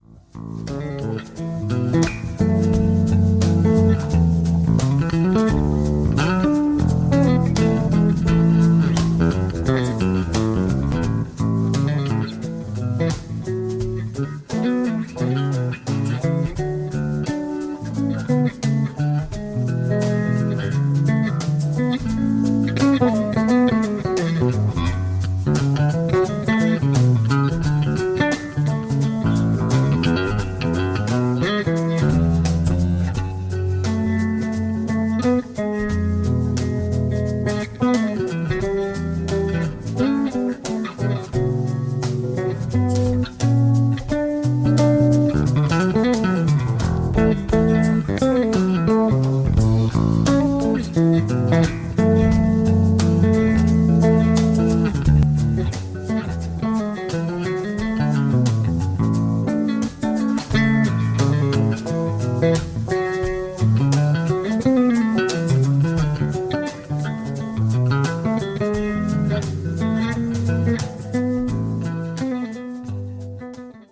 Umschaltung in folgender Reihenfolge: Original/Mono - Binaural - Remix/Mono - Binaural - Original/Mono - Binaural - Remix/Mono. "Original/Mono" ist wie der Name erwarten lässt das Eingangssignal.